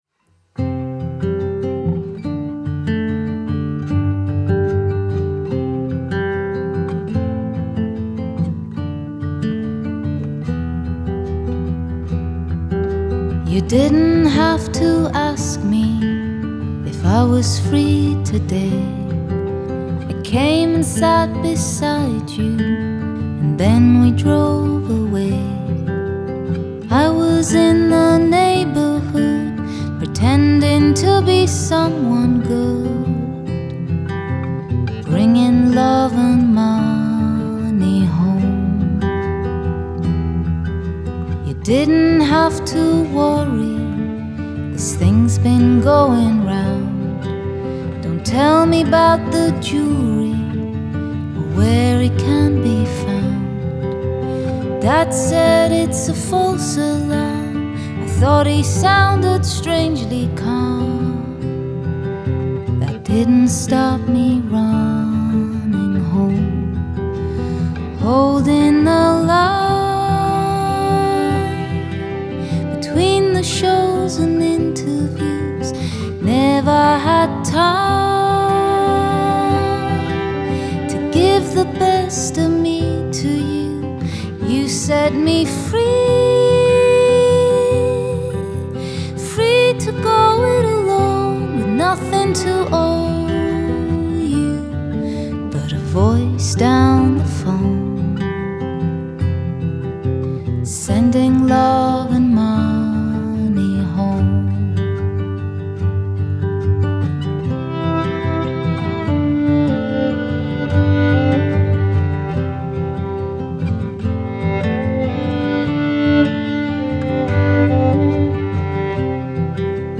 Genre : Alternative, Folk